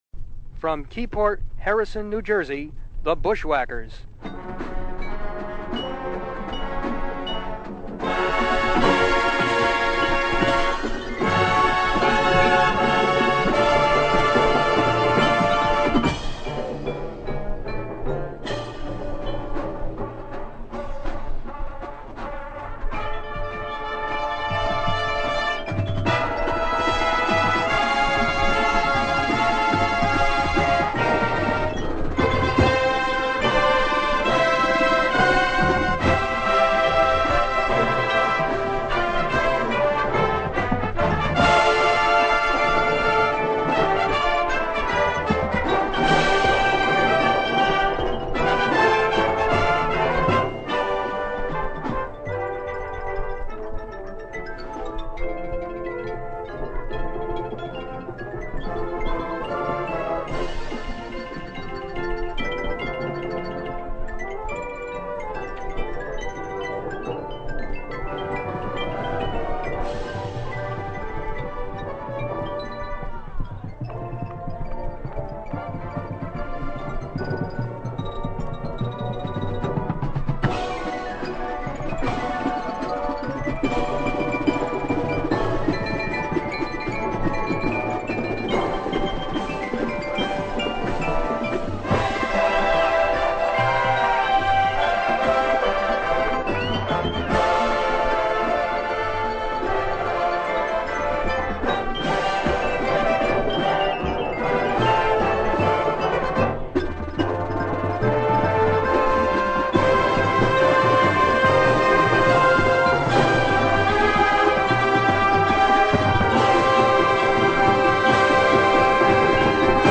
1982 DCA Championships - Bushwackers Drum & Bugle Corps